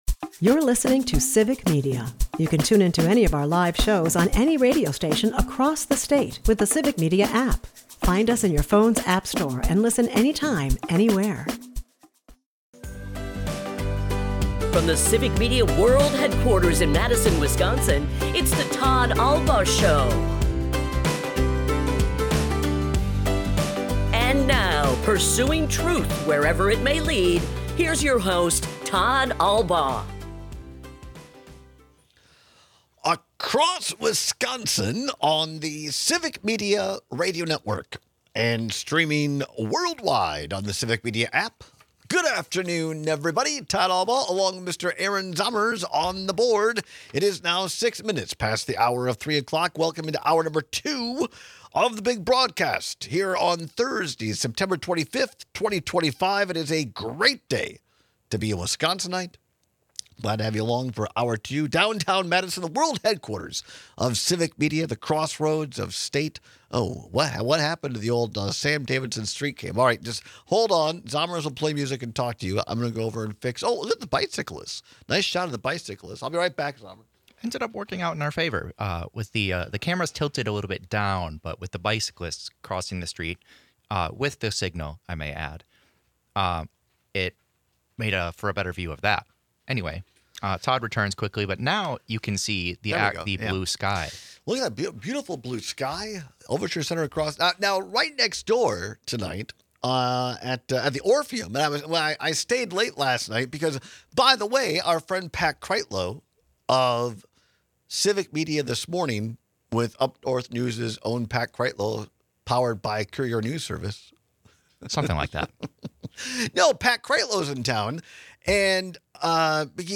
We take your calls and texts on which season plugs you up more.&nbsp